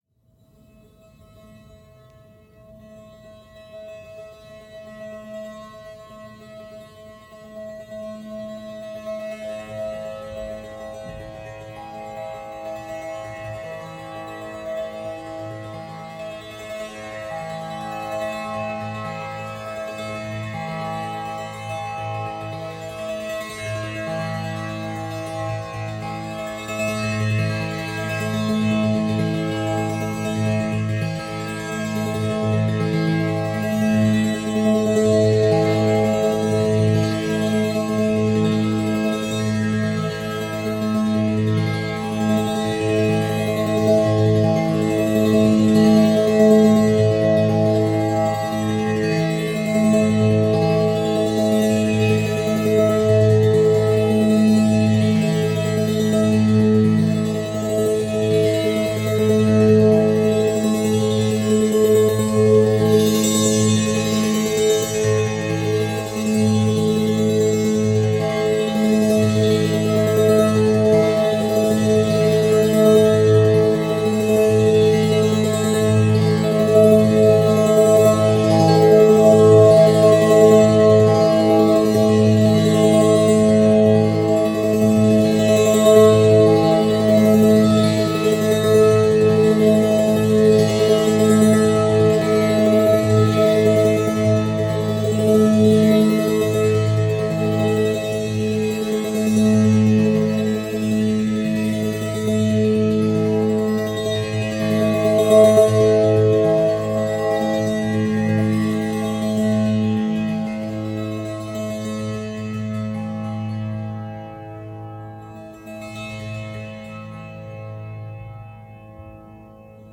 Monolini A Monochord therapy sequence - Am - 120.mp3
Original creative-commons licensed sounds for DJ's and music producers, recorded with high quality studio microphones.
monolini_a_monochord_therapy_sequence_-_am_-_120_eqx.ogg